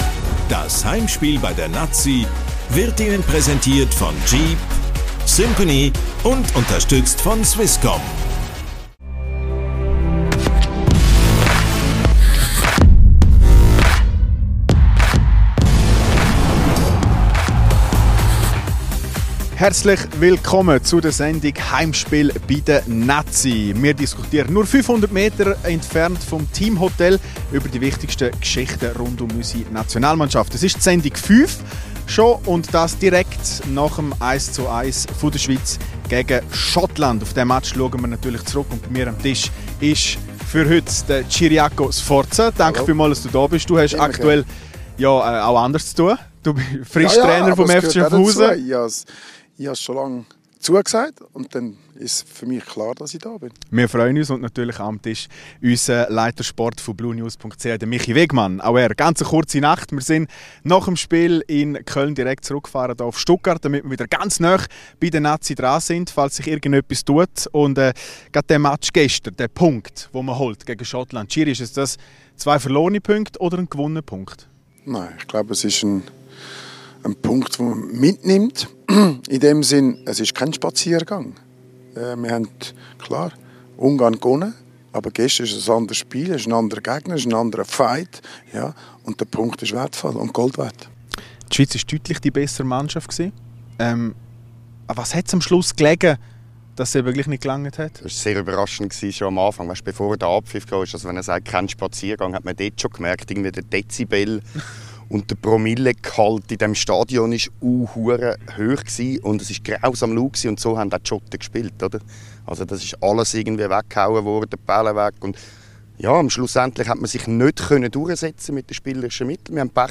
Heimspiel bei der Nati – der EM-Talk von blue Sport.